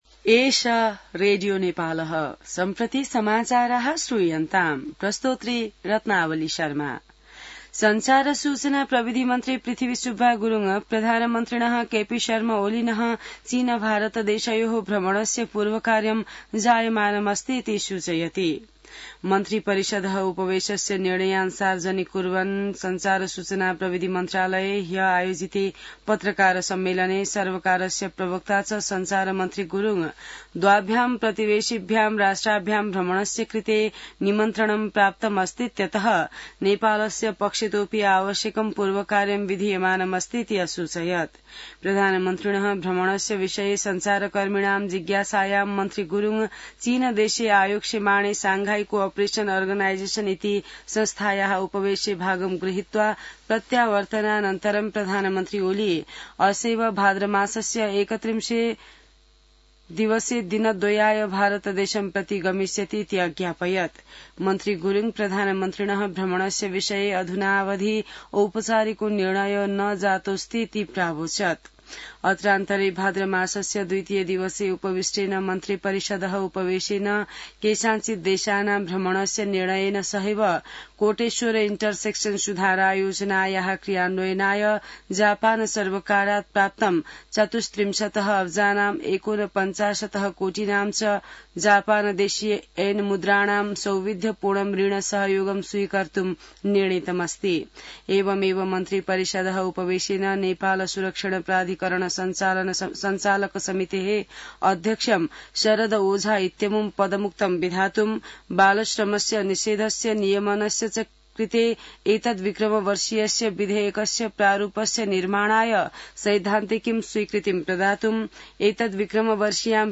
संस्कृत समाचार : ५ भदौ , २०८२